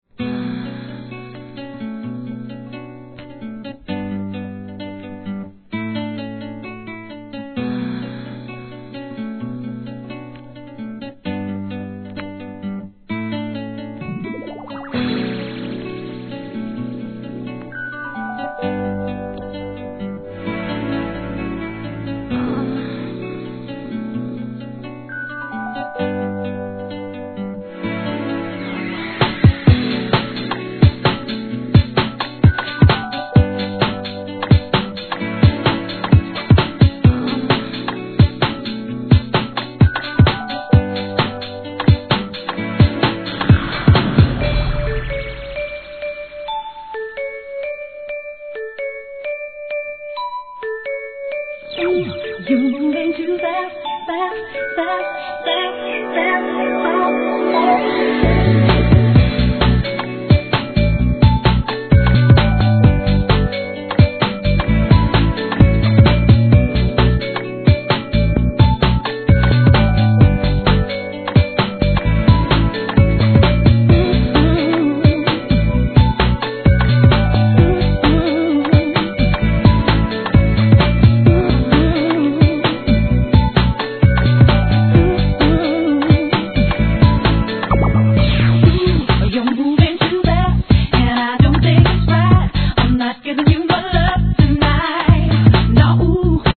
2000年、当時ダンス・ミュージックの最新形態で流行の2 STEP物!